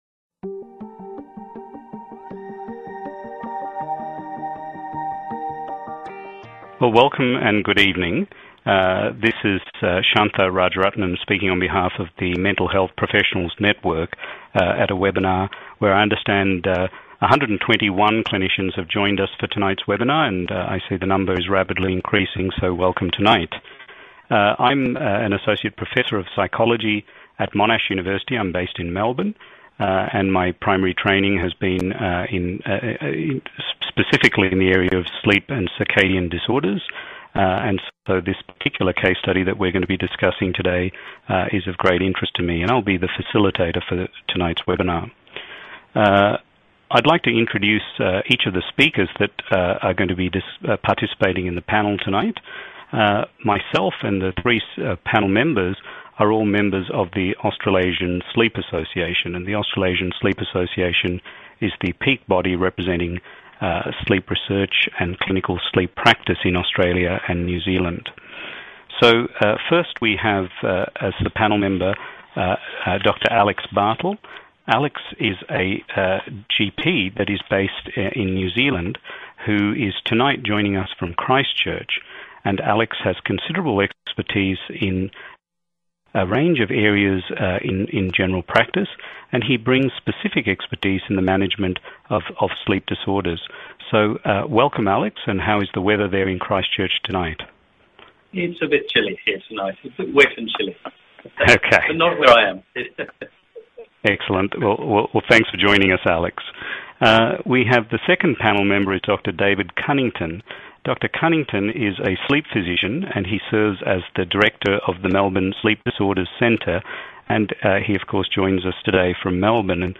The panel discuss her care from an interdisciplinary perspective.
Panellists Discussion